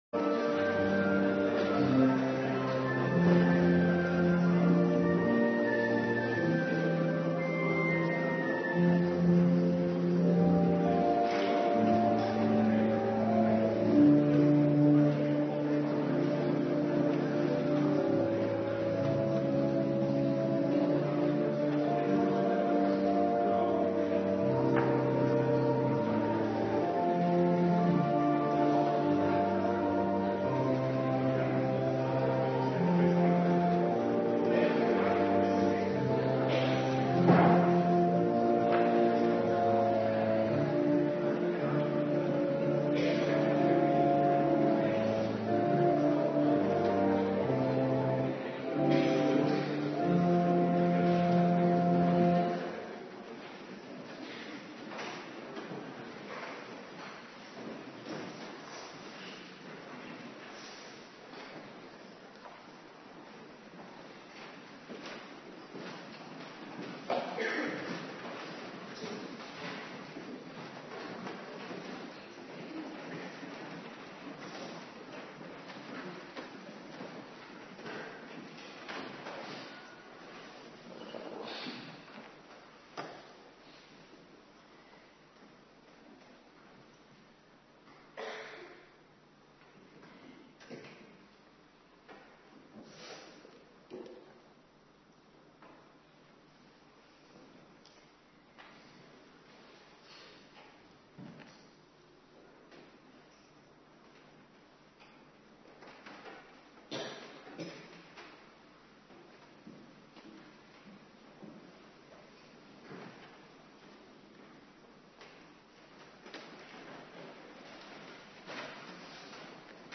Preken (tekstversie) - Geschriften - Dordtse Leerregels hoofdstuk 5 par 3 | Hervormd Waarder